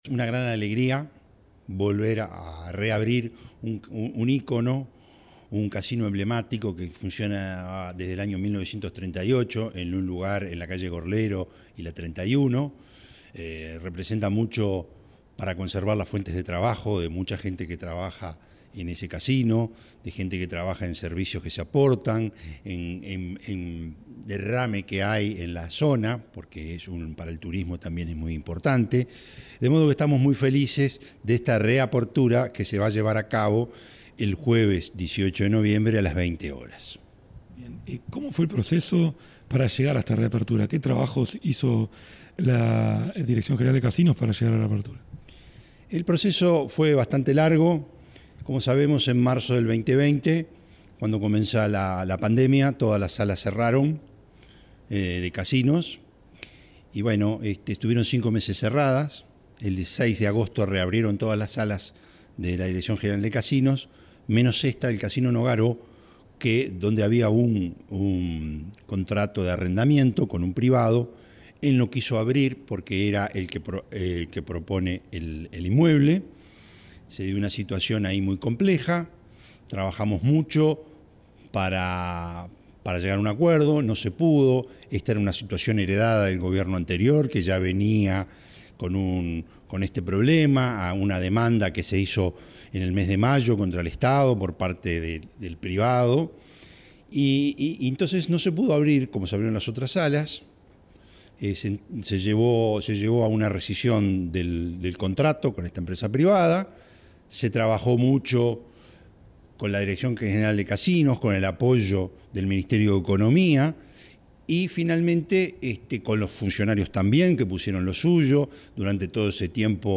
Entrevista al director general de Casinos, Gustavo Anselmi